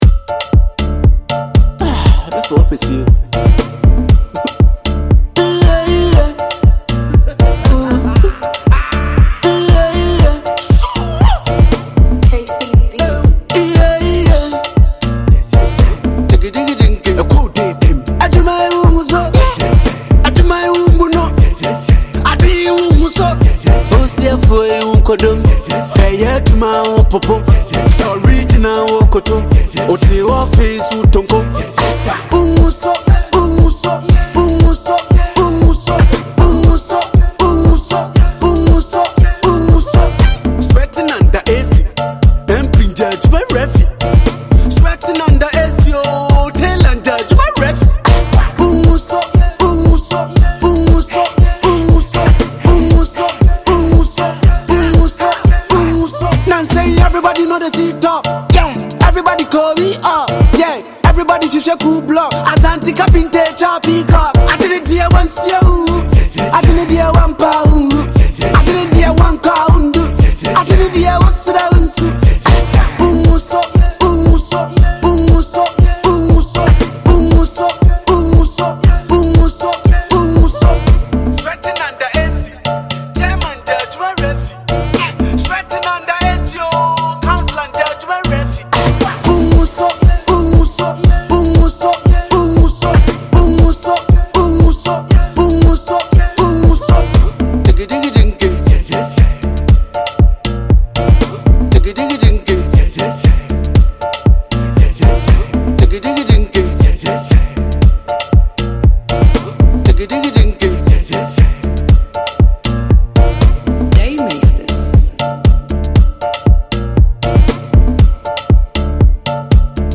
Ghana MusicMusic
smashing feet dazzling tune